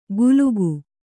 ♪ gulugu